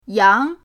yang2.mp3